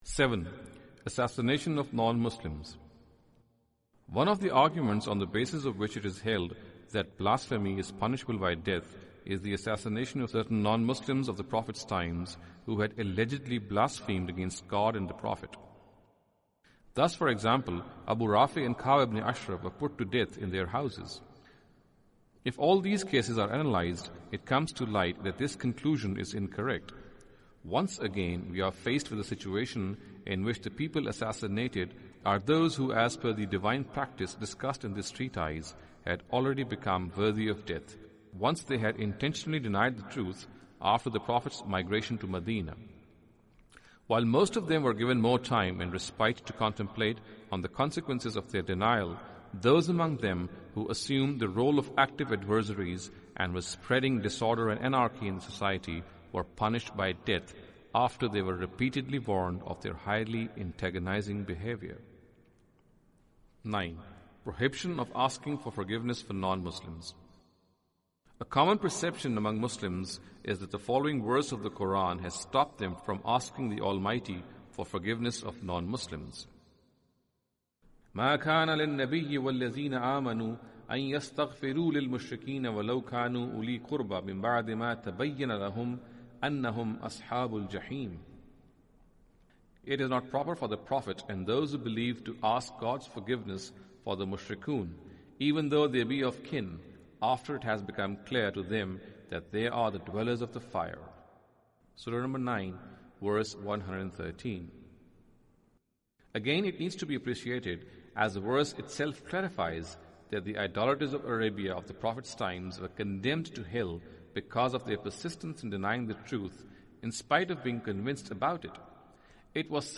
Audio book of English translation of Javed Ahmad Ghamidi's book "Playing God".